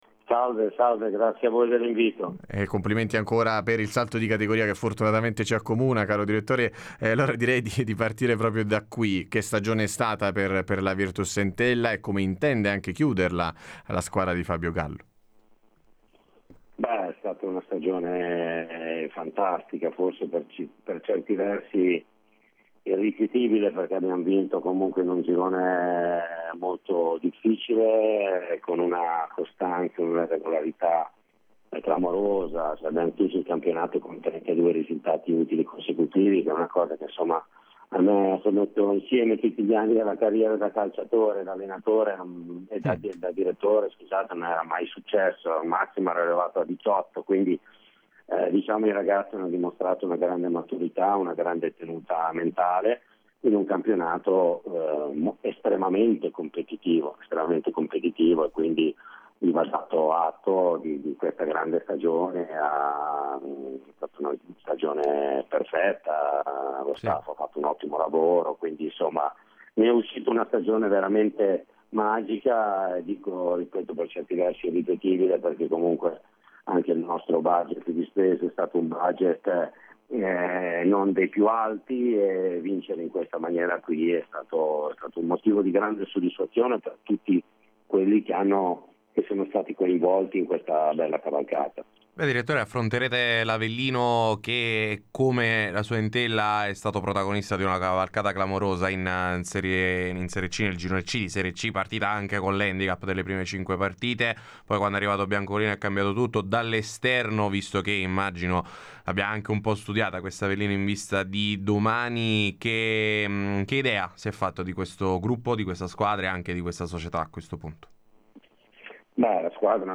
In diretta a Radio Punto Nuovo